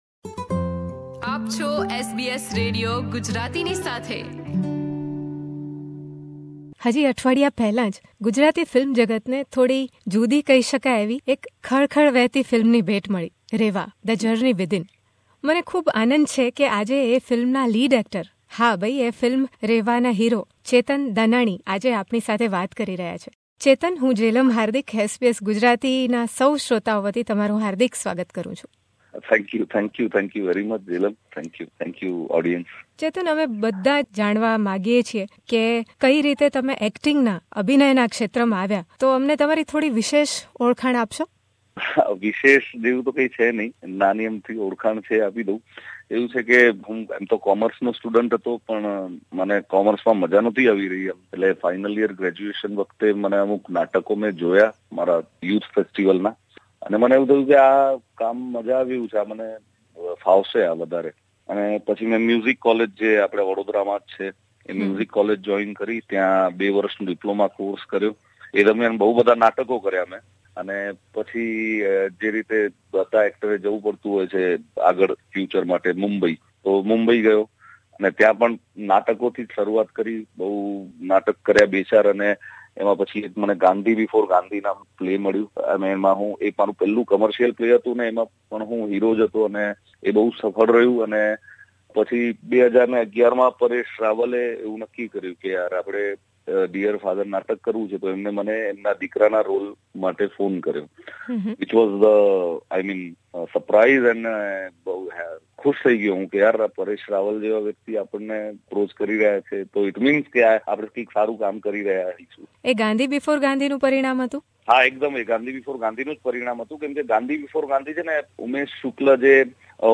He preferred acting to Commerce stream. In this conversation, he shares how he loved being part of the latest Gujarati film 'Reva'.